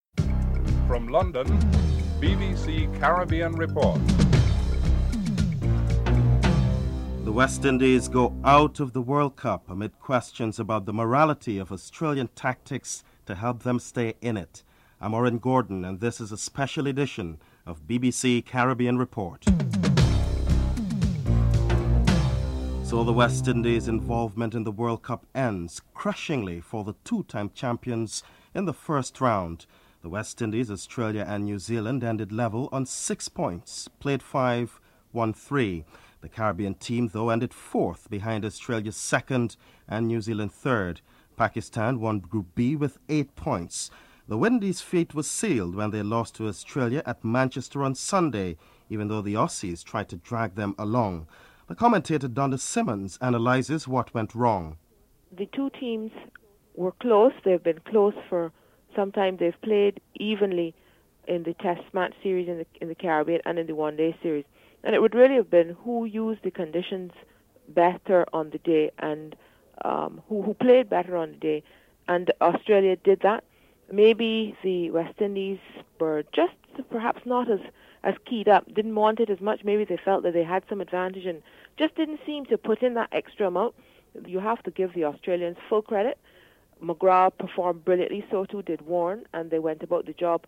Team captains Brian Lara and Steve Waugh review the series. Sports journalist Jeremy Coney comments on qualification matches.
Cricket fans opinions are solicited on key matches.